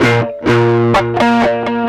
Track 10 - Guitar 03.wav